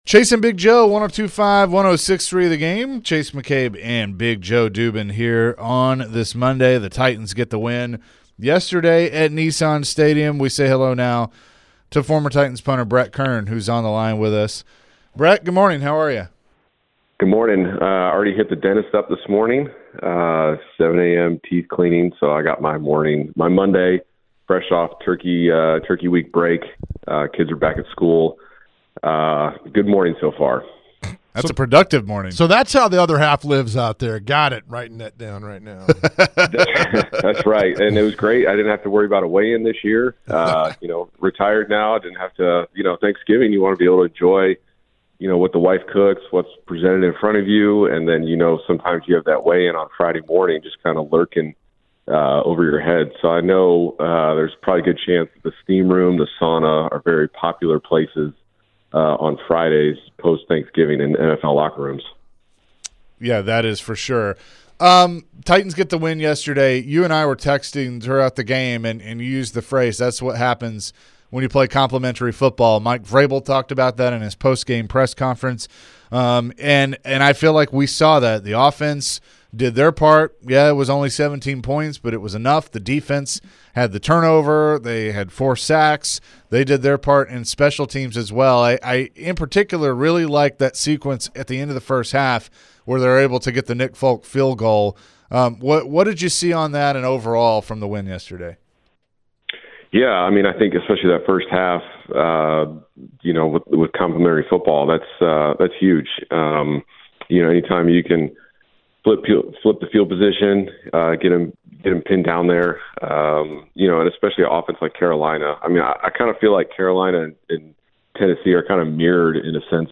Former Titans punter Brett Kern Joined the show and shared his thoughts on the Titans home win yesterday to the Panthers. Plus, Brett shared his thoughts on the big takeaways from the game yesterday.